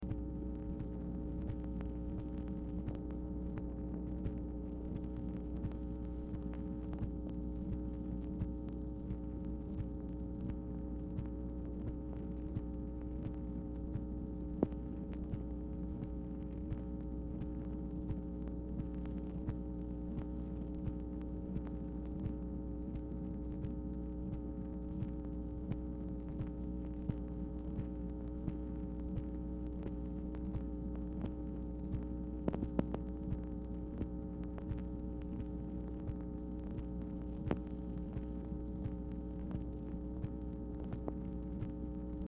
Telephone conversation # 11389, sound recording, MACHINE NOISE, 1/21/1967, time unknown | Discover LBJ